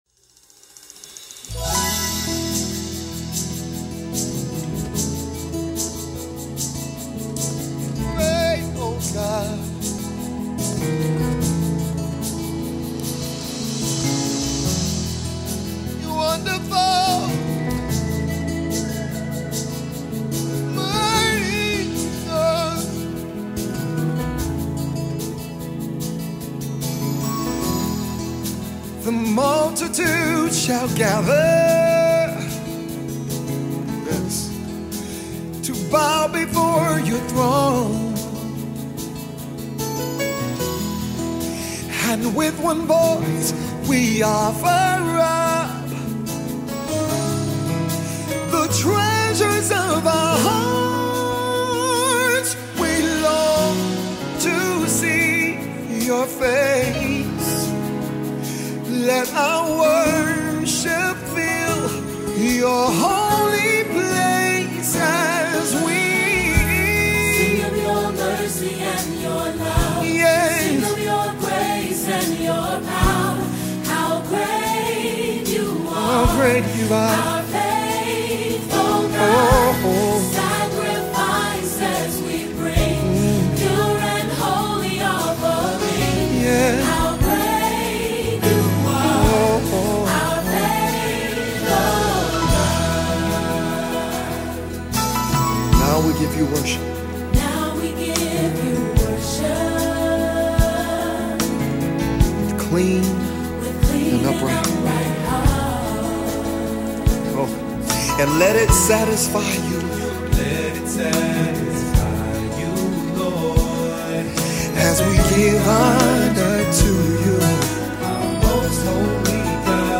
Top Christian Songs
with the multi-octave voice